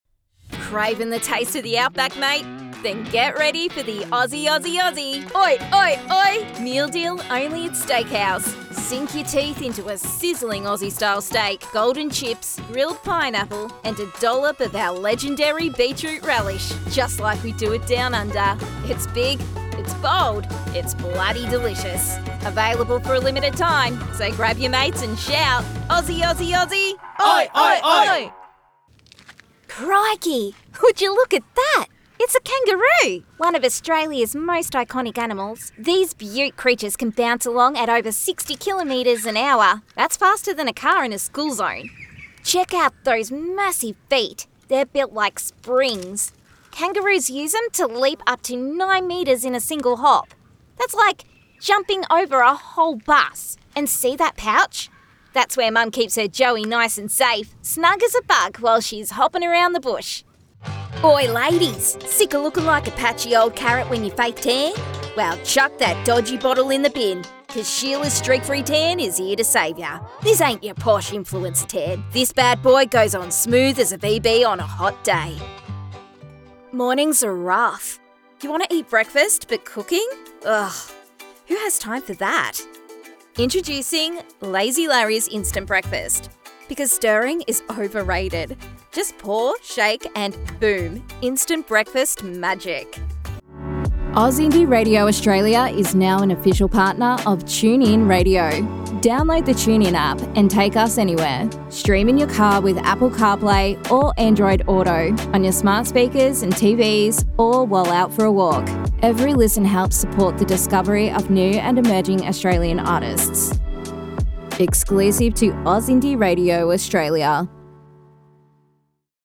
Female
Warm , versatile , dynamic and engaging . Large vocal range.
Television Spots
A Very Aussie Demo